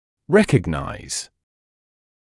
[‘rekəgnaɪz][‘рэкэгнайз]узнавать; распознавать